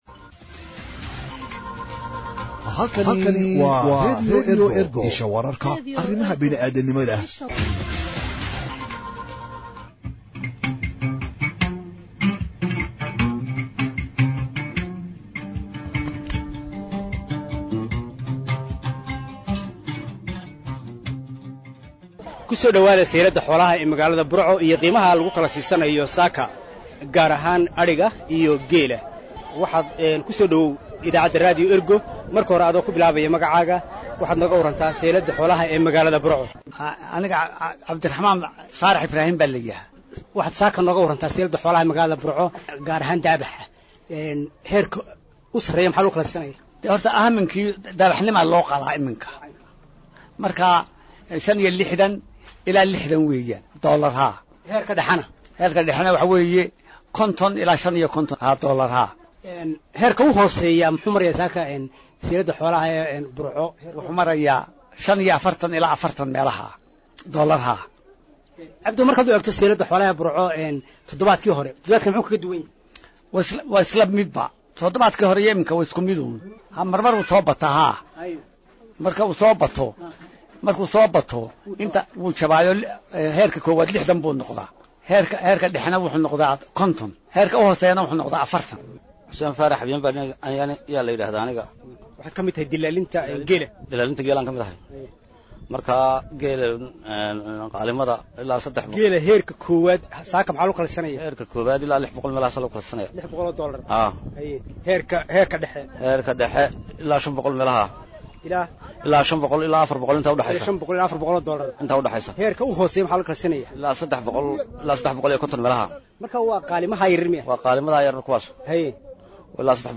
ayaa beeca xoolaha ka wareystay mid ka mid ah dallaaliinta suuqa.